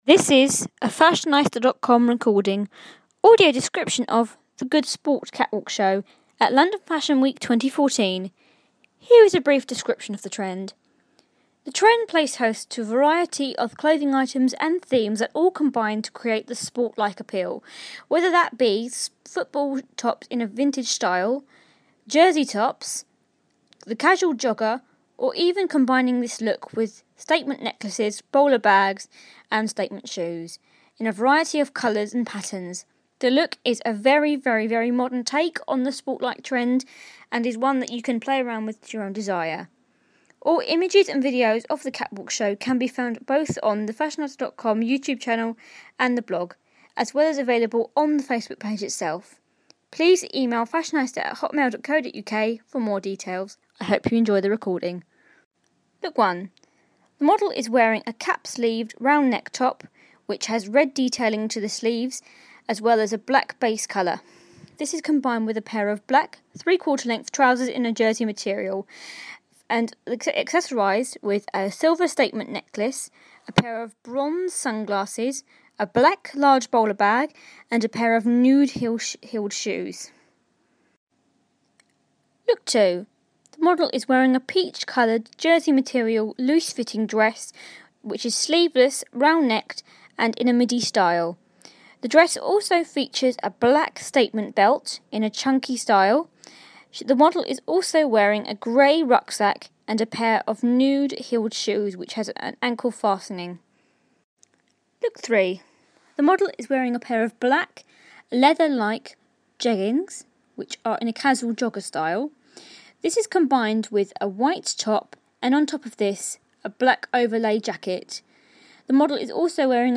Audio Description of The Good Sport Catwalk Show